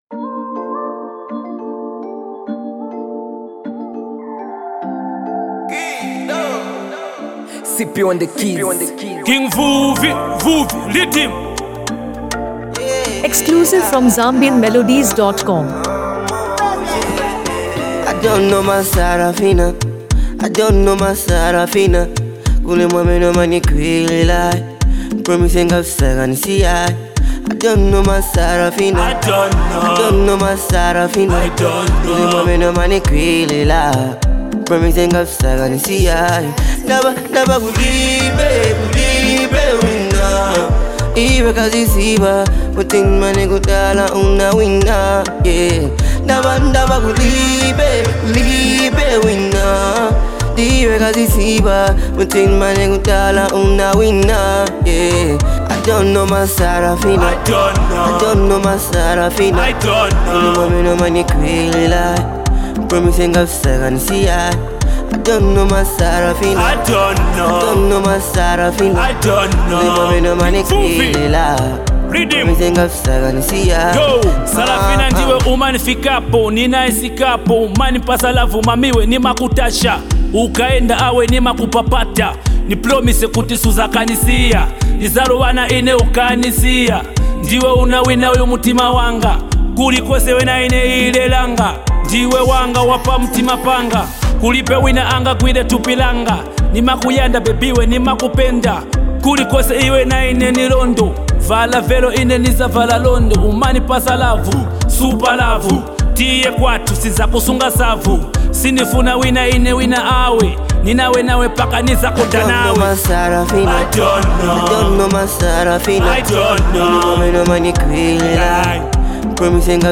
Lusaka Trending Afro Song
Genre: Afro-beats